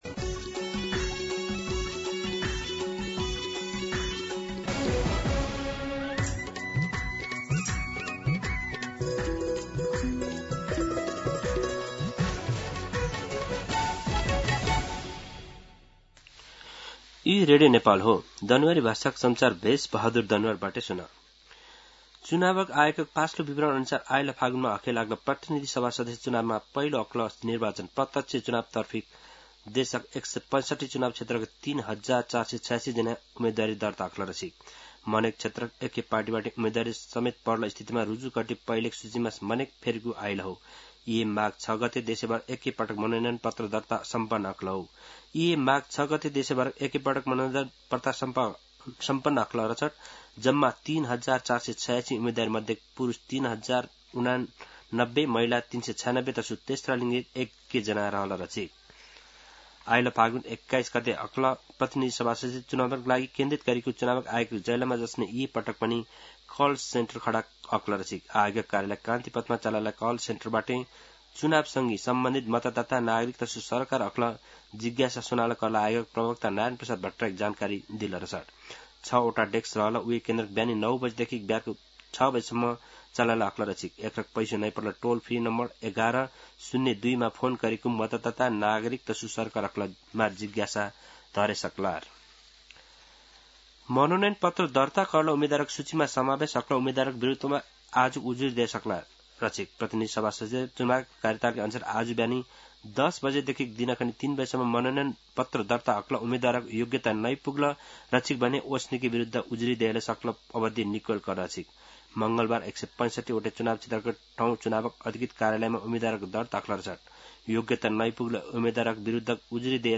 दनुवार भाषामा समाचार : ७ माघ , २०८२
Danuwar-News-10-7.mp3